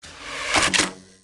disparodoble_segundo_disparo.mp3